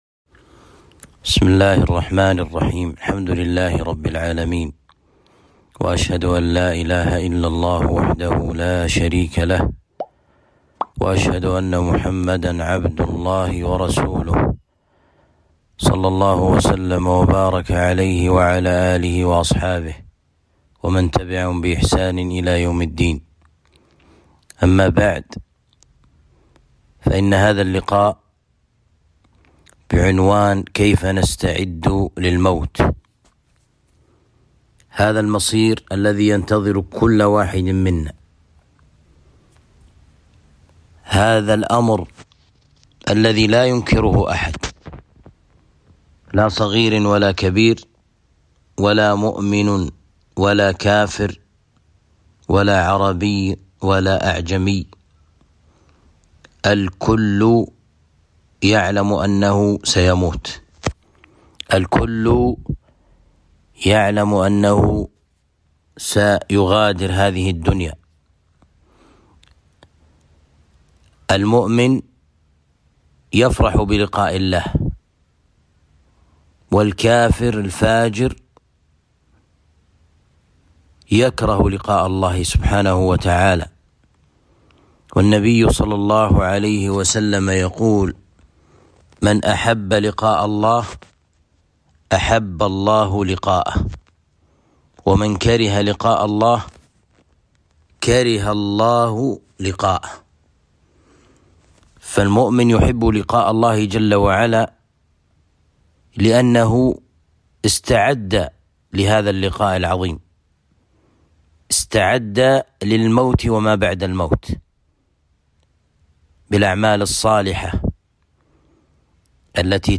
الصوتيات المحاضرات